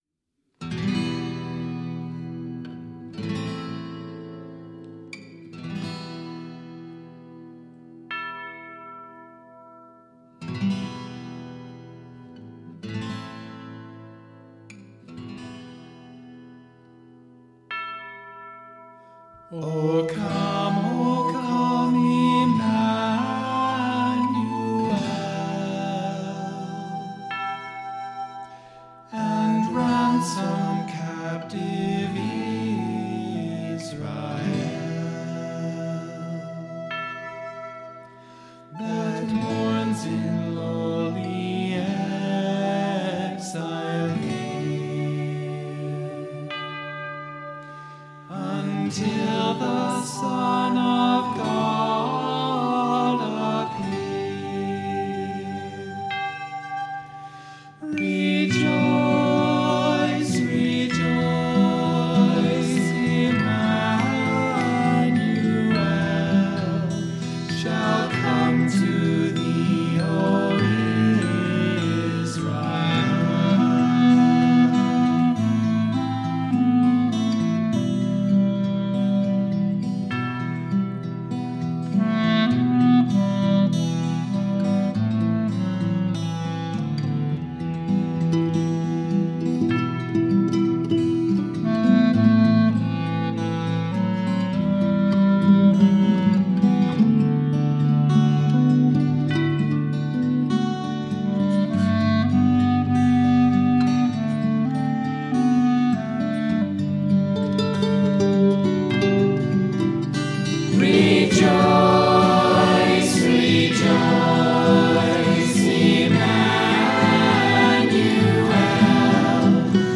Here is our family Christmas recording for 2019:
“O Come, O Come, Emmanuel” is an old European carol.
It is haunting and beautiful, full of pathos and tension, the kind of song that needs to be sung in an old cathedral. We didn’t have a cathedral handy, so we had to make do with Dragonfly Reverb instead.
All of the family participated except for the youngest, and all of the instruments are real except for the tubular bells.